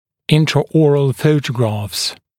[ˌɪntrə’ɔːrəl ‘fəutəgrɑːfs][ˌинтрэ’о:рэл ‘фоутэгра:фс]внутриротовые фотографии